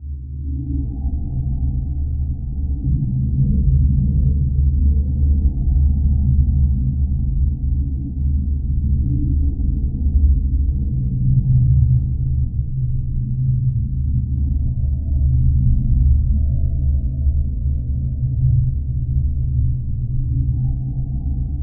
Index of /90_sSampleCDs/Spectrasonic Distorted Reality 2/Partition G/01 DRONES 1